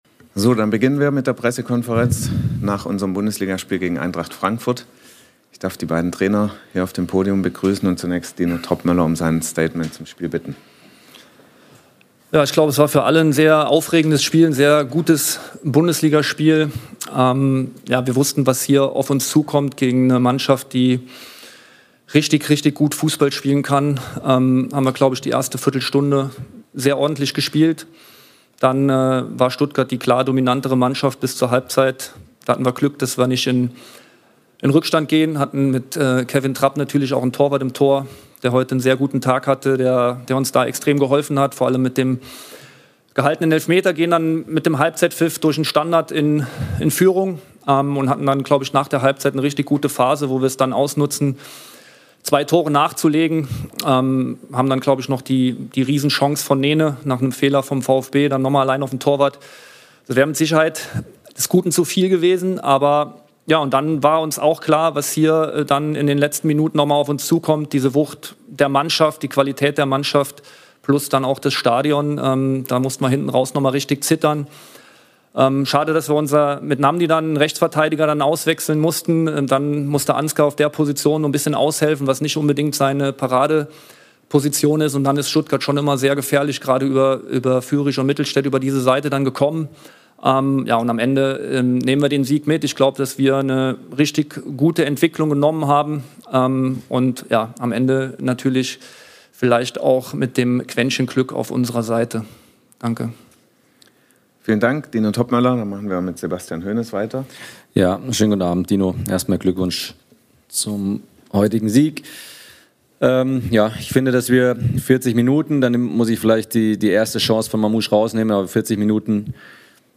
Die Pressekonferenz nach unserem 3:2-Auswärtssieg beim VfB Stuttgart mit beiden Cheftrainern.